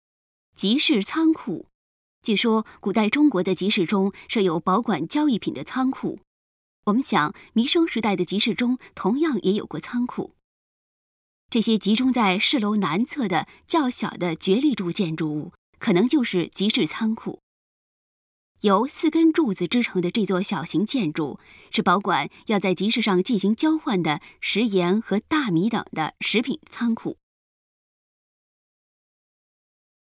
由4根柱子支撑的这座小型建筑，是保管要在集市上进行交换的食盐和大米等的食品仓库。 语音导览 前一页 下一页 返回手机导游首页 (C)YOSHINOGARI HISTORICAL PARK